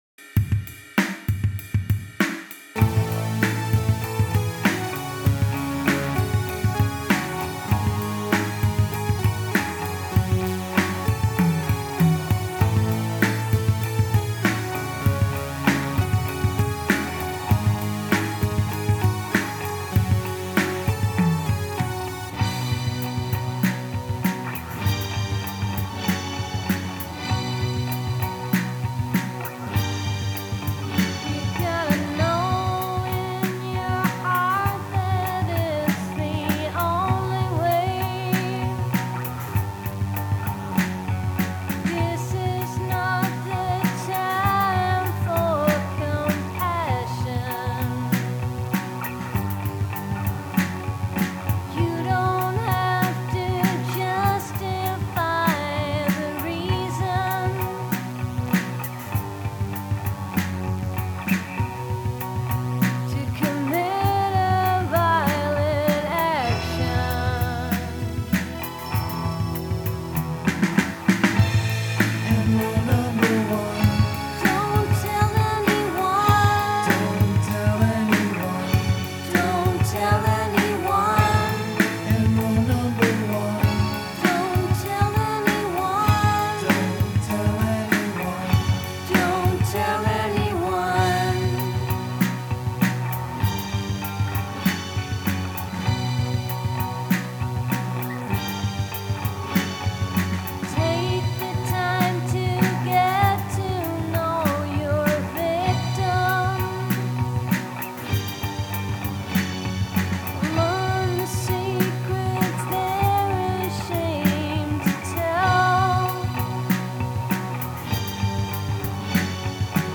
lead vocals, keyboard, backing vocals
Omnichord, Folktek modified Omnichord, backing vocals
guitar, drum programming, backing vocals